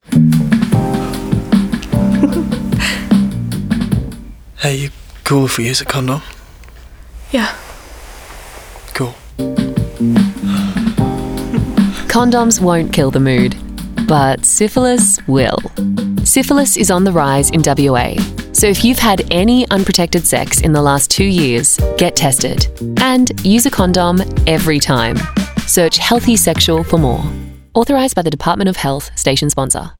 Health-Dpt-Syphillis-Radio-30-Alt-v5.wav